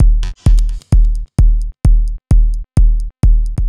Big Kick.wav